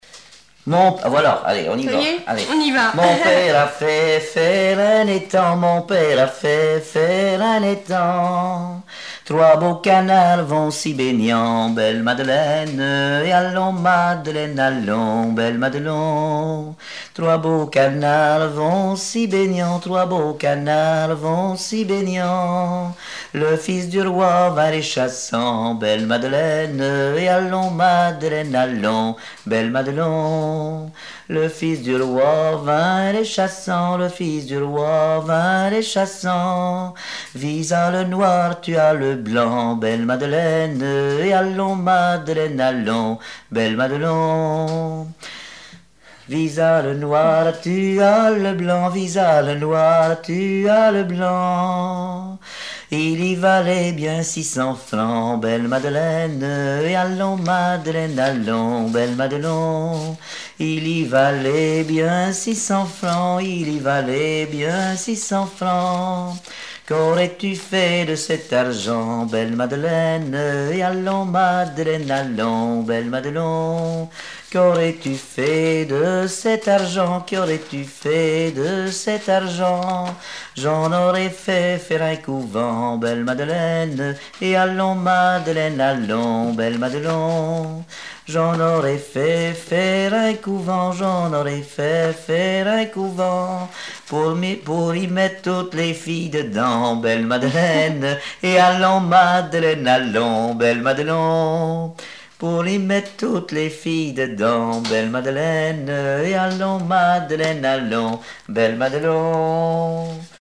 Fonction d'après l'analyste gestuel : à marcher
Genre laisse
Répertoire de chansons traditionnelles et populaires
Pièce musicale inédite